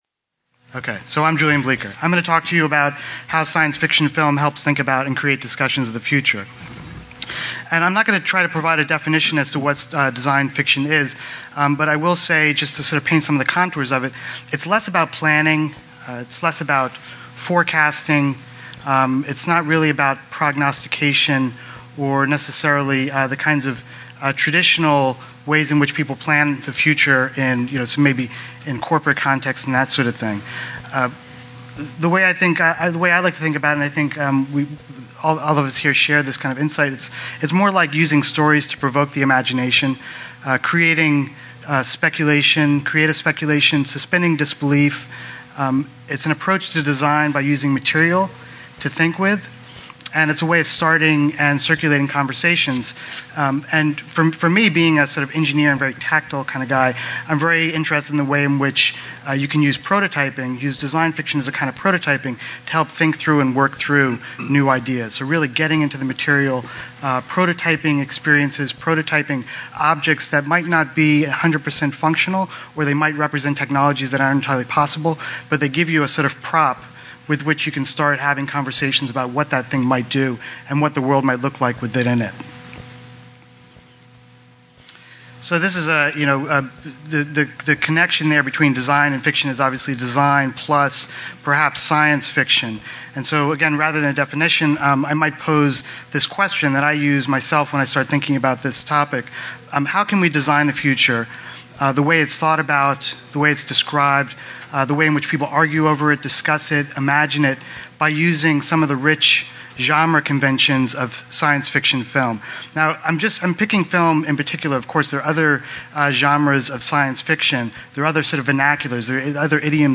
SxSW panel on Design Fiction